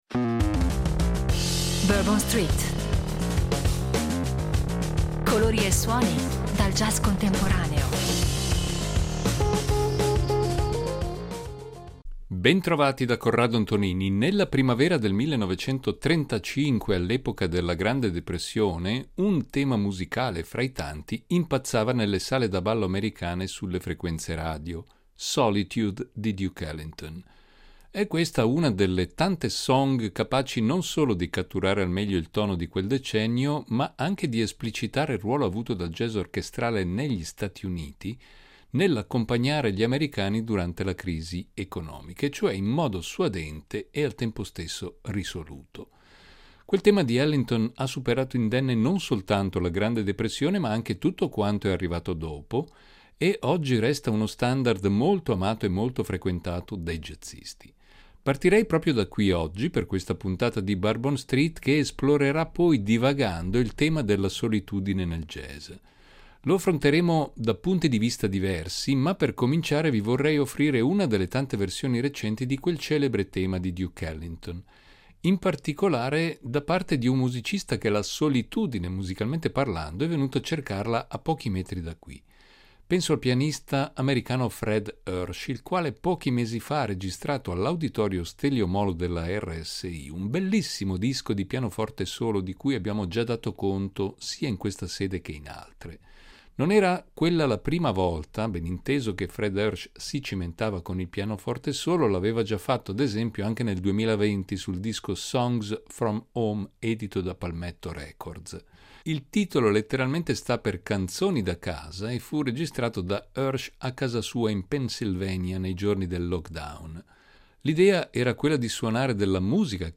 Un viaggio musicale tra i temi solitari del jazz